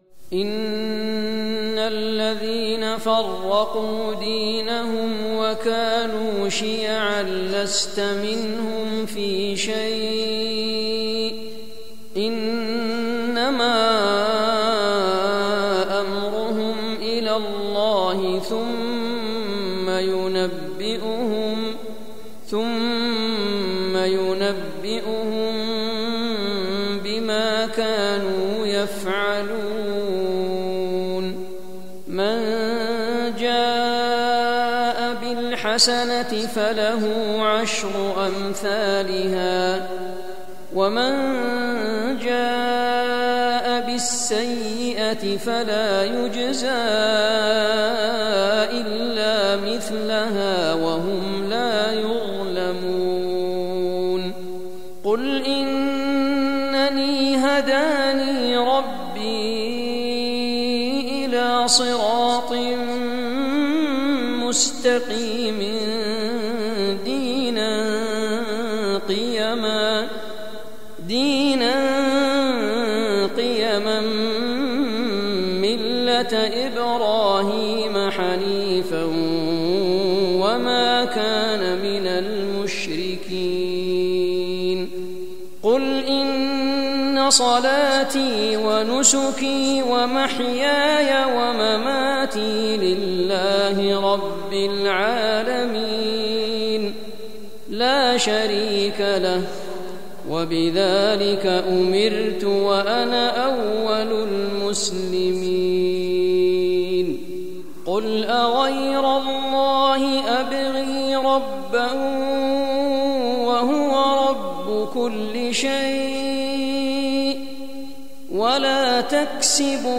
تلاوة خاشعة مميزة
تلاوة من سورة الأنعام للشيخ
مسجد بلال ، المدينة المنورة